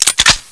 deagle_cin2.wav